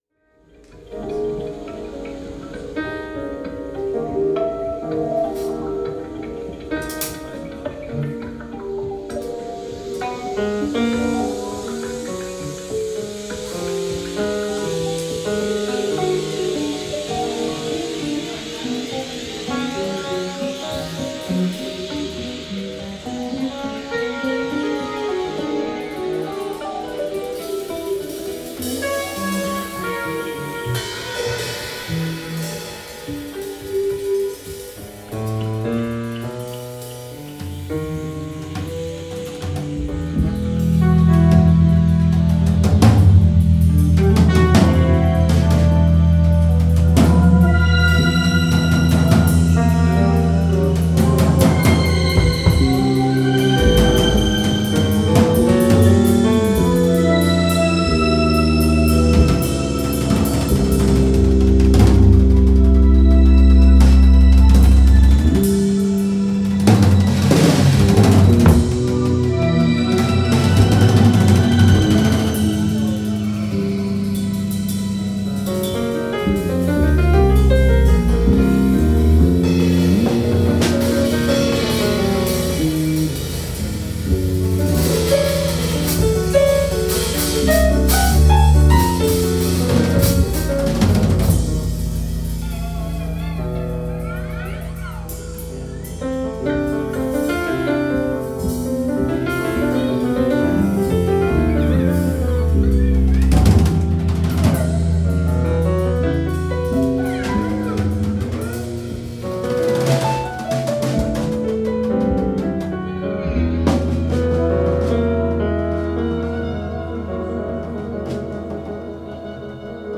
live bei der Jazznacht Soest am 07.02.2026
Keyboard/Synthesizer
Flügelhorn/E-Gitarre
E-Gitarre
Bass/Samples
Schlagzeug